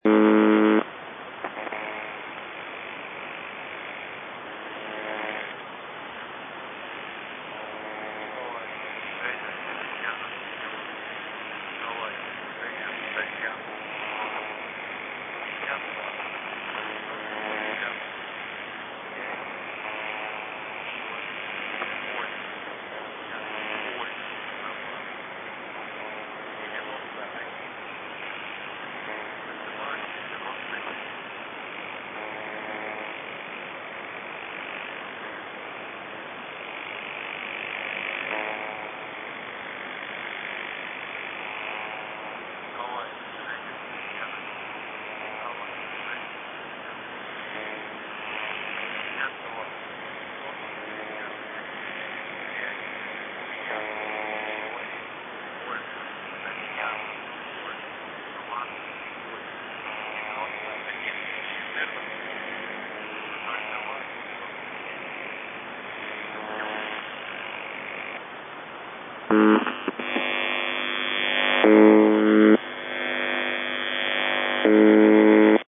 Военная "Жужжалка" на частоте 4625 кГц. "The Buzzer" - АНВФ, УЗБ-76, МДЖБ, ЖУОЗ - Страница 191
Насколько важна данная сеть сейчас, если её телефонная частота с февраля работает с, практически, парализованным НЧ трактом?